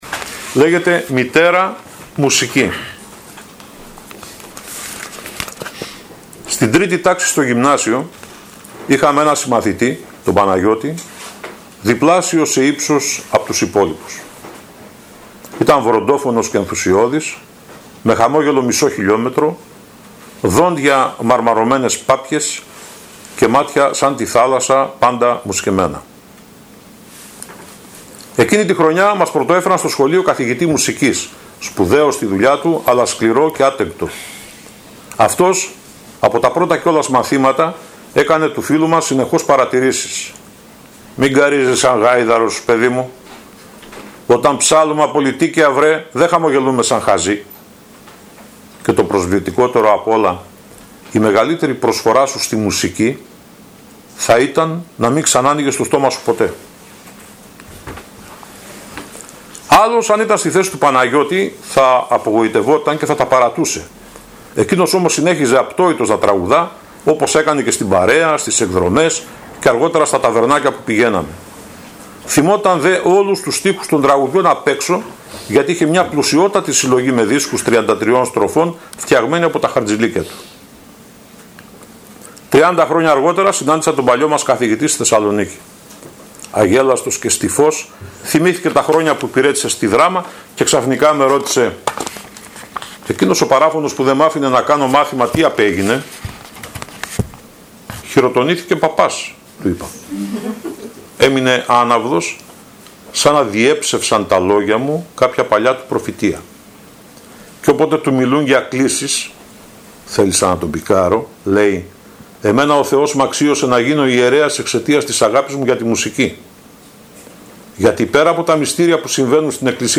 απόσπασμα από την παρουσίαση στην Ξάνθη
Ανάγνωση